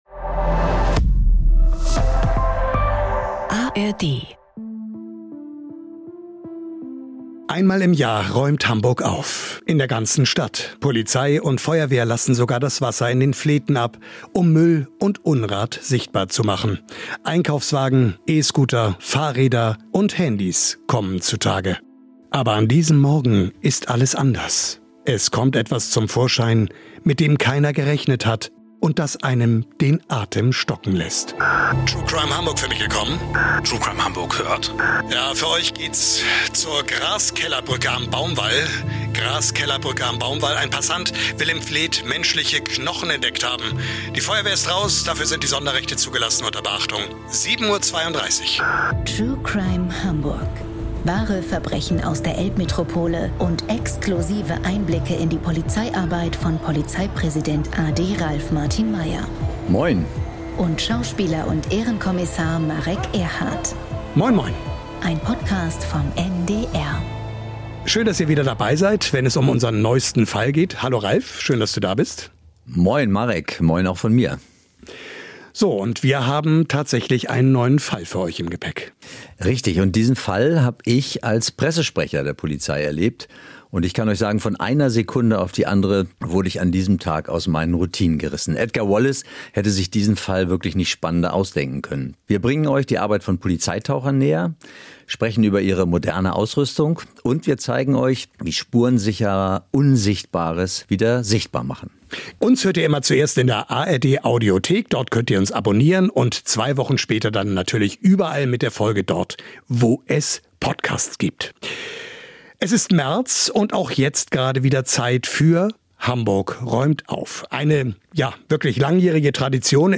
Im Gespräch mit Schauspieler Marek Erhardt spricht Hamburgs Polizeipräsident a.D. Ralf Martin Meyer über die anspruchsvolle Arbeit von Polizeitauchern und ihre moderne Ausrüstung. Und er erklärt, wie Spurensucher unsichtbares sichtbar machen.